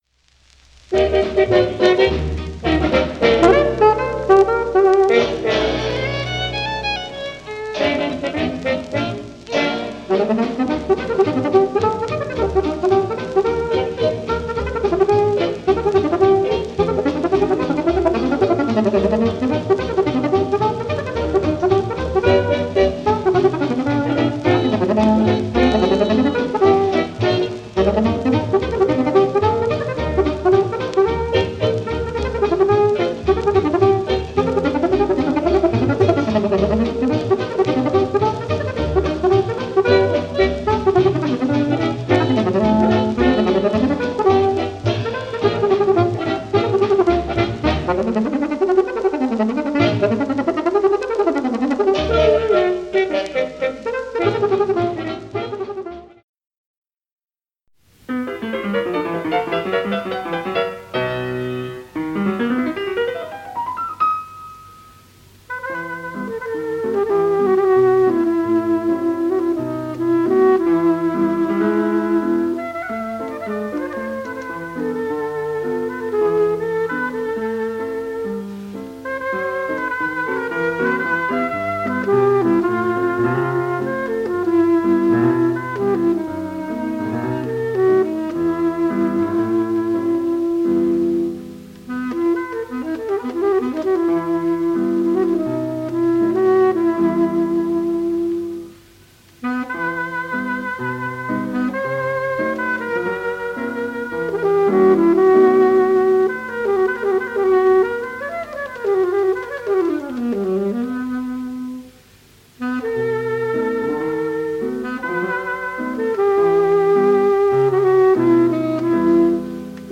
ASax, Pno